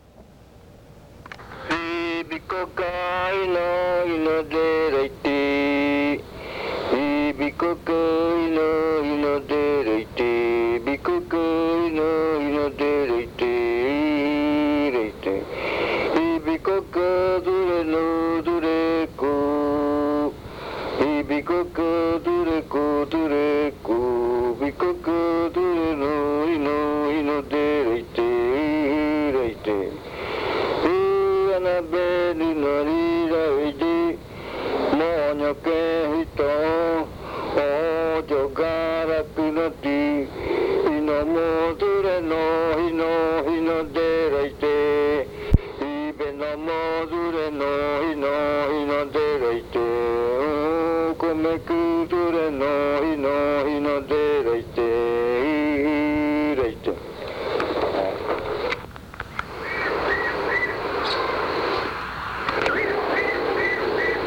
Leticia, Amazonas
Canto hablado (uuriya rua). Sátira.
Spoken chant (uuriya rua).
This chant is part of the collection of chants from the Yuakɨ Murui-Muina (fruit ritual) of the Murui people